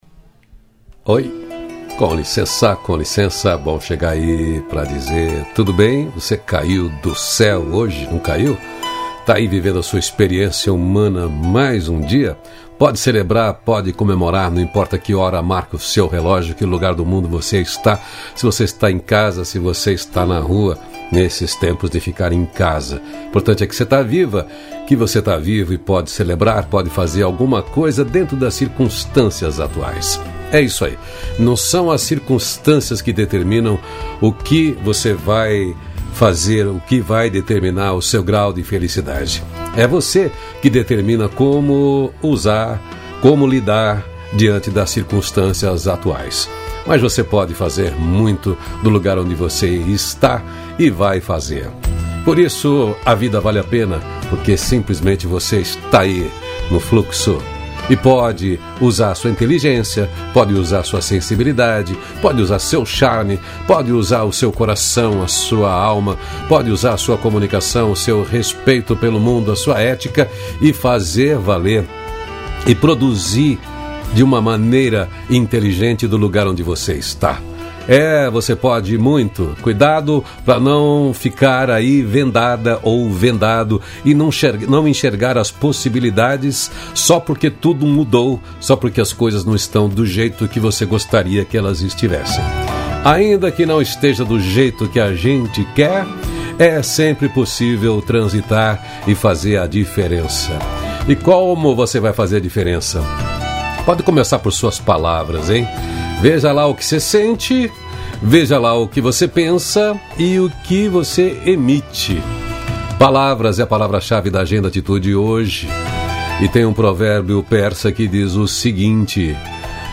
Trilha sonora: Loreena Mckennitt "Between the shadows"